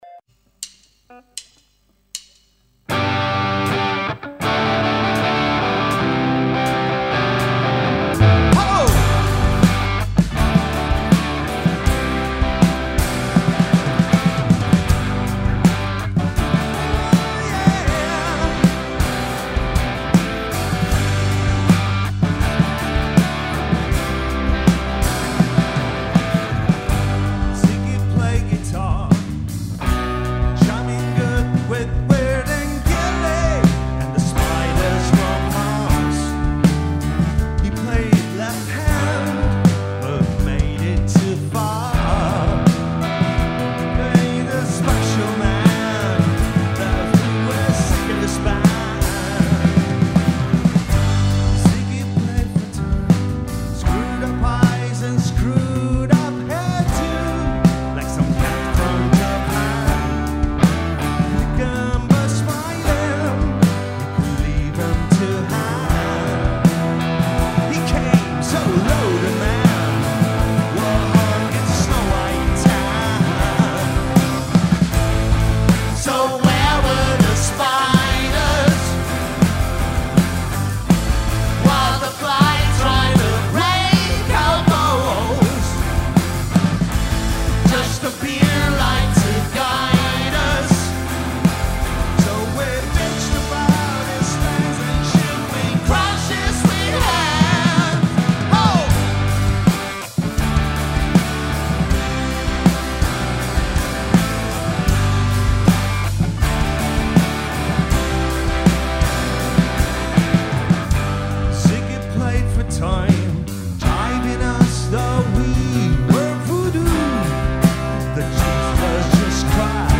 concerto in diretta
voce e chitarra
basso
voce e tastiere
batteria
sintetizzatore